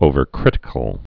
(ōvər-krĭtĭ-kəl)